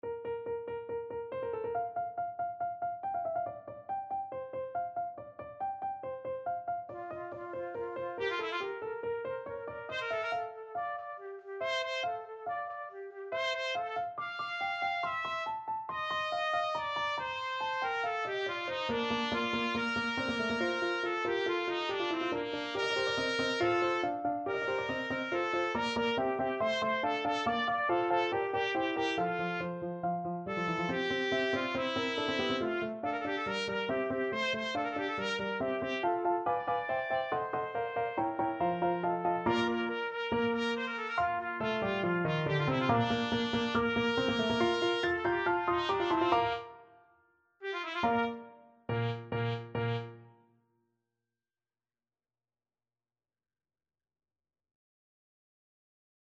Classical Mozart, Wolfgang Amadeus Magic Flute Overture Trumpet version
Bb major (Sounding Pitch) C major (Trumpet in Bb) (View more Bb major Music for Trumpet )
4/4 (View more 4/4 Music)
= 140 Allegro (View more music marked Allegro)
Trumpet  (View more Intermediate Trumpet Music)
Classical (View more Classical Trumpet Music)
MagicFluteOverture_TPT.mp3